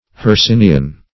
Search Result for " hercynian" : The Collaborative International Dictionary of English v.0.48: Hercynian \Her*cyn"i*an\, a. [L. Hercynia silva, Hercynius saltus, the Hercynian forest; cf. Gr.